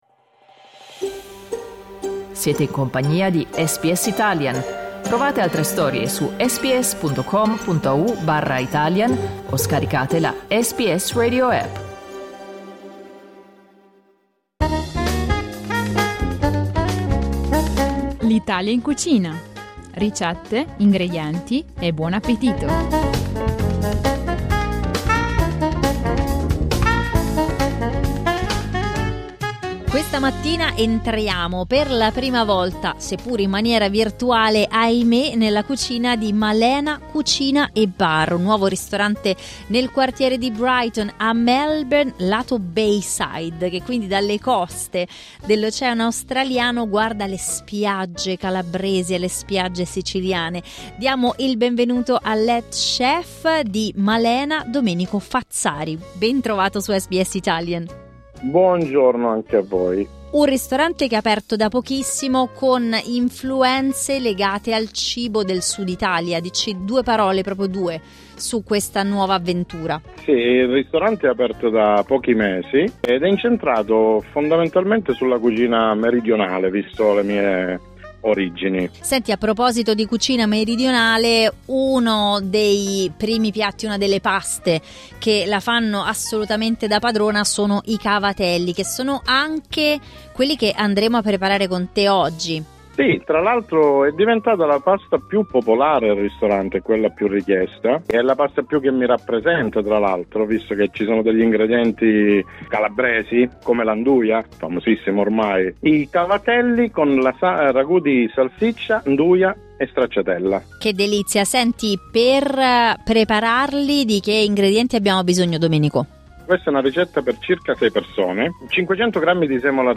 Clicca sul tasto "play" in alto per ascoltare l'audio ricetta